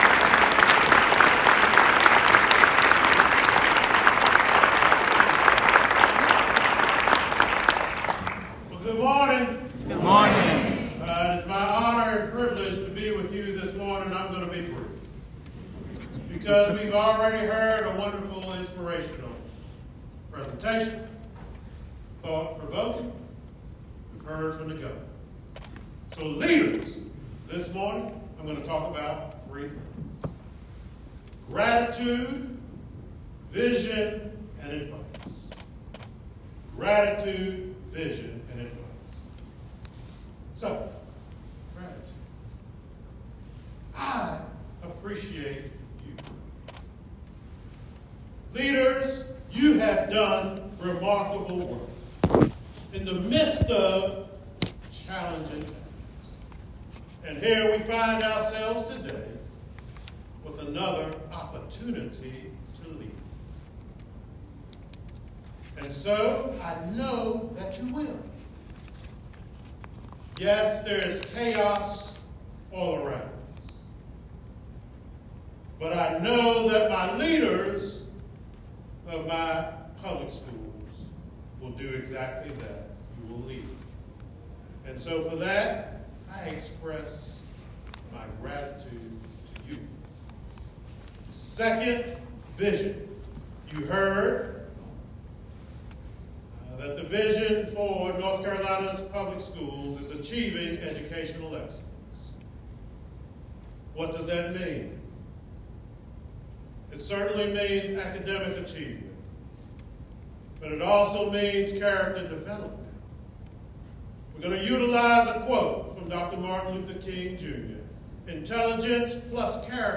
Green later took the stage for a dynamic speech that addressed the crowd of superintendents, principals, and other leaders in North Carolina public education.
State Superintendent of Public Instruction Maurice “Mo” Green speaks at the 2025 NCASA Conference on Educational Leadership.